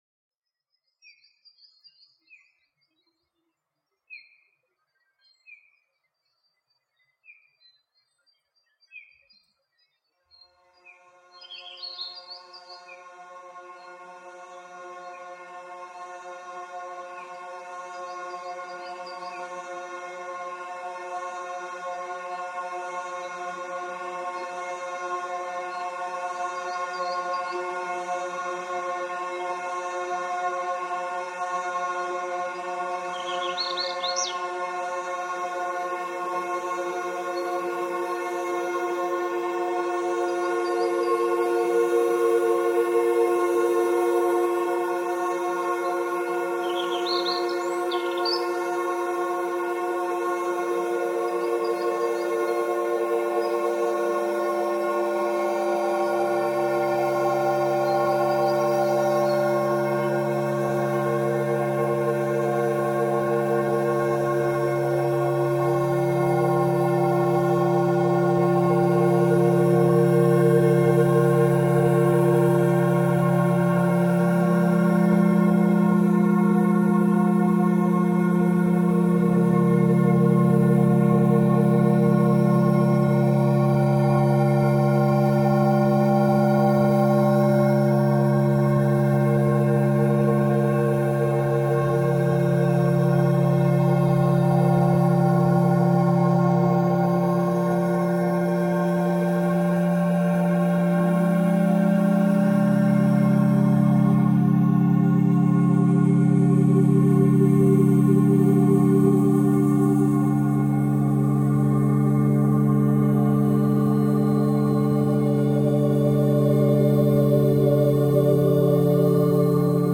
Armenian monastery reimagined